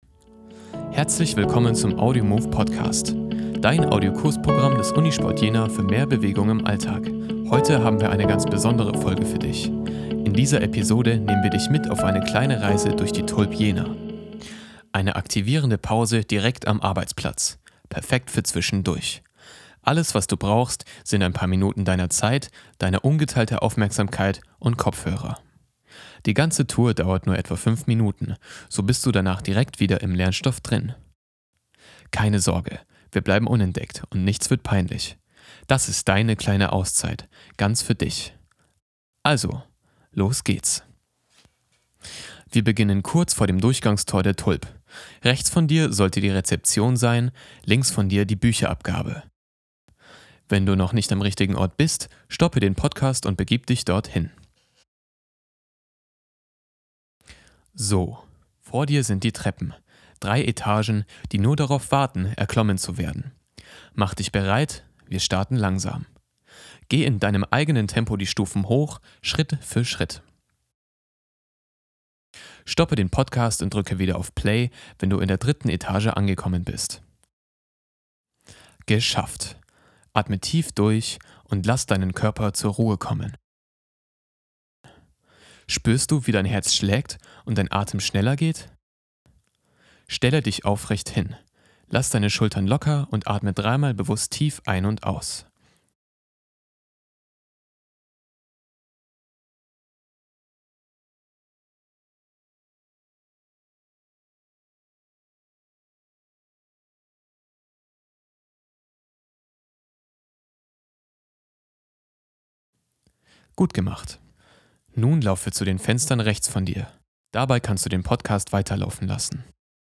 Wir versorgen dich mit motivierenden Tipps, geführten Übungen und spannenden Einblicken rund um den Unisport Jena.